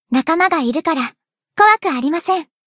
贡献 ） 分类:彩奈 分类:蔚蓝档案语音 协议:Copyright 您不可以覆盖此文件。